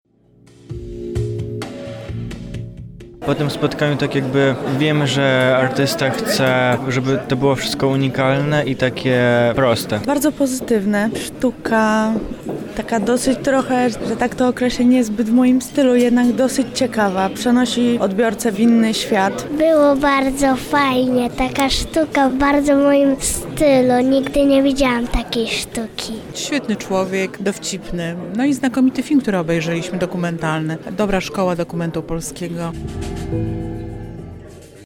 Wzięliśmy udział w wernisażu tego artysty.